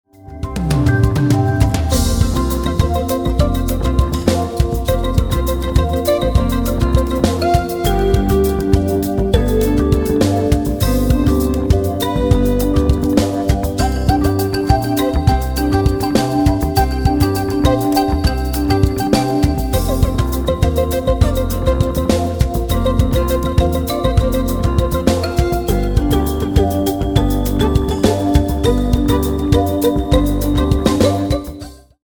It is in the style of rock.